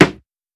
CDK Dope Snare.wav